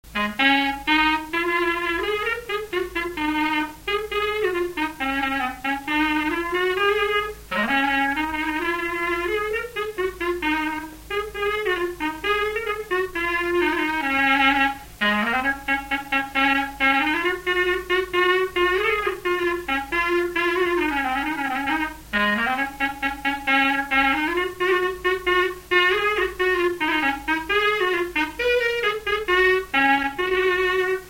Résumé instrumental
danse : ronde : du balai
Pièce musicale inédite